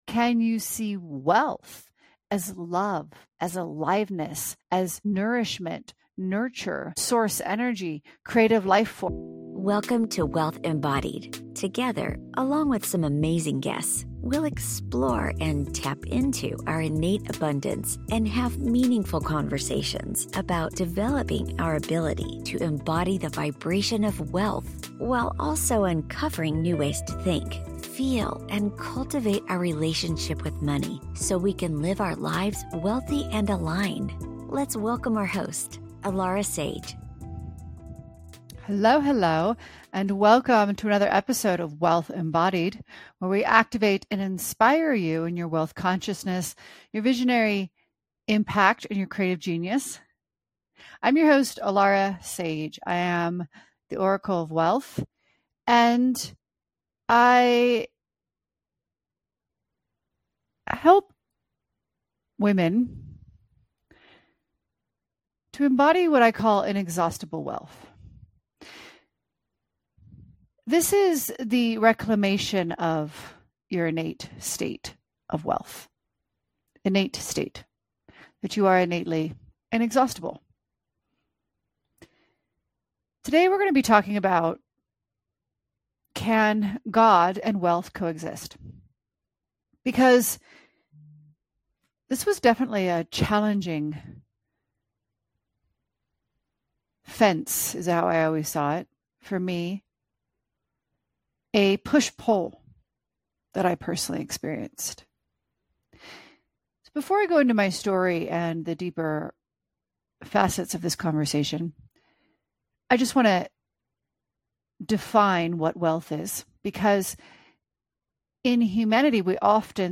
Conversations and guest interviews to help you learn how to activate wealth consciousness, embody the frequency of wealth and apply financial strategies to your life. Listen to compelling conversations and insights on Quantum Mechanics, the Law of Attraction, Manifestation, and Wealth Creation and Management.